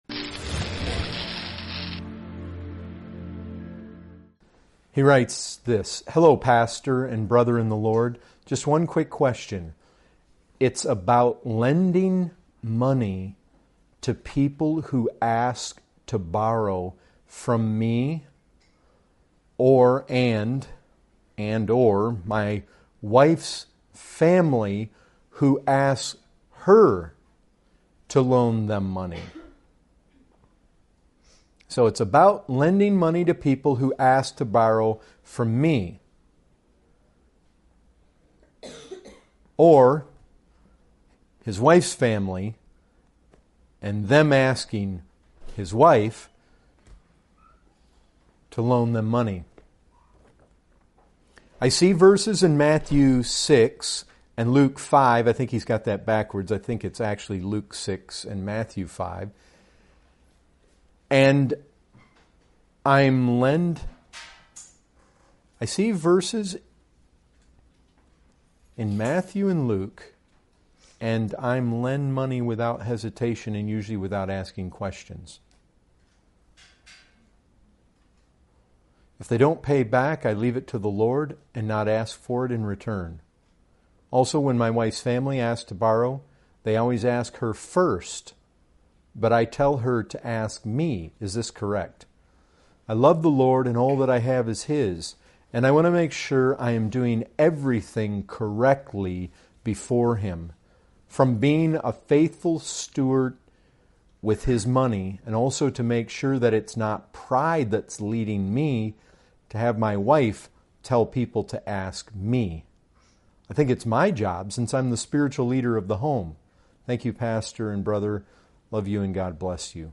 Questions & Answers